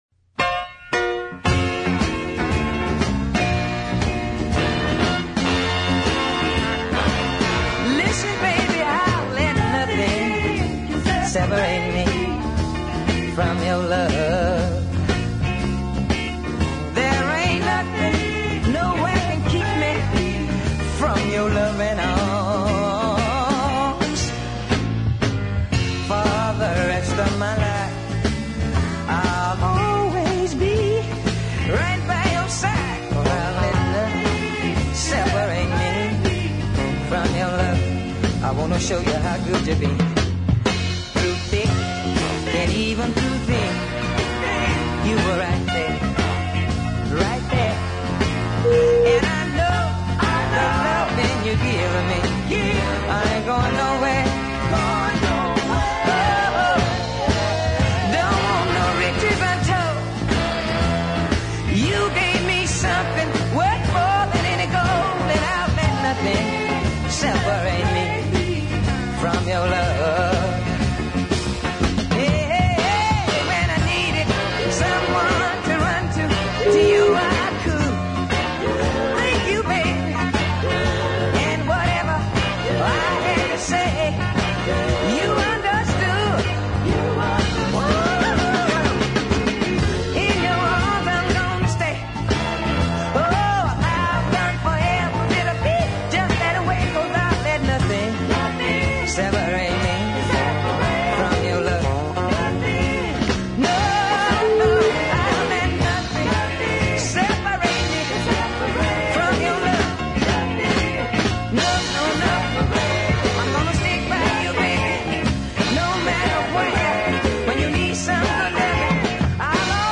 lovely bluesy cadences
hard West Coast music